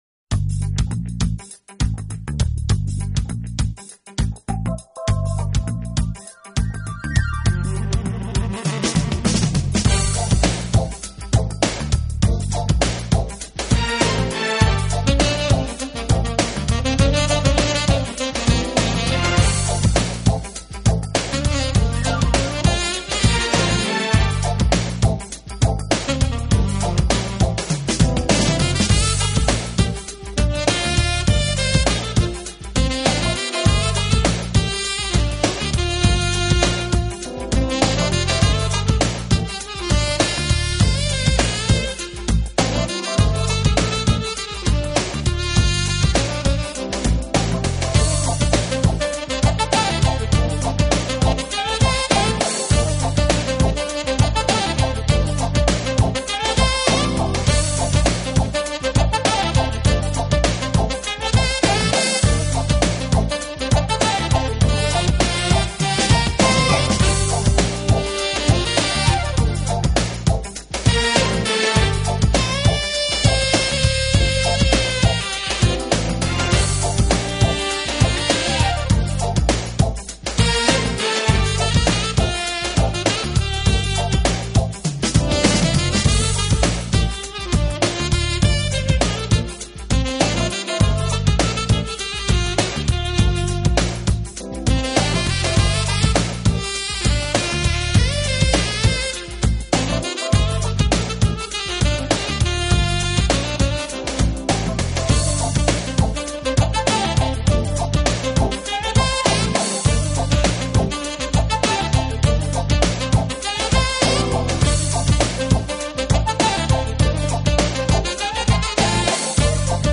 【爵士萨克斯】
音乐类型:  Smooth  Jazz
之中，一切都轻松愉快地进行着，曲调间飞扬着喜悦之情，果然是件美事～～唯一的问
总得感觉：一张高水平地流行爵士专辑。